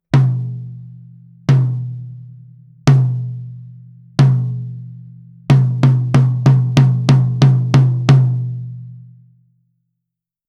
実際の録り音
タム
58ドラムタム.wav